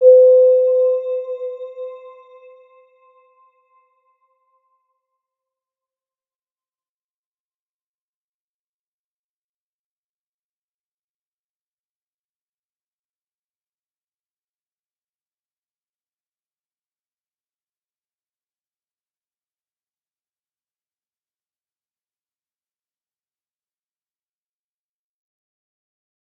Round-Bell-C5-mf.wav